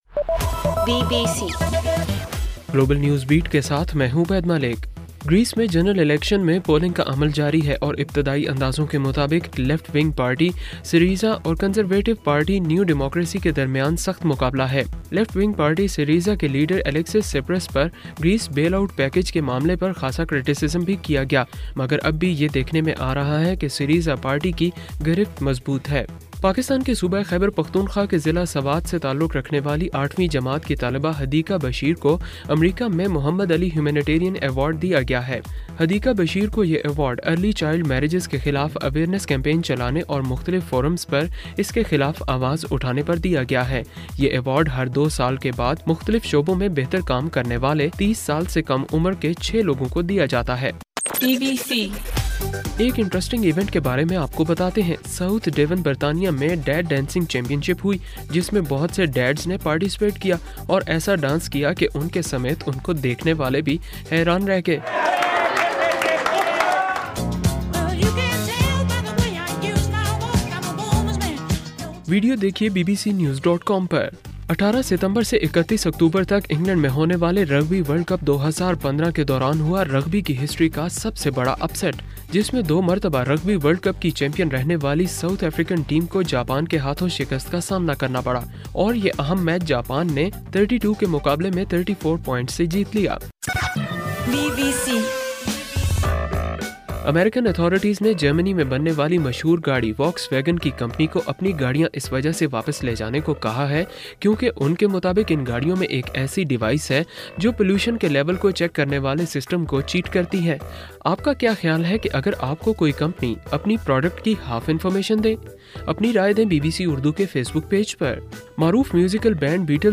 ستمبر 20: رات 11 بجے کا گلوبل نیوز بیٹ بُلیٹن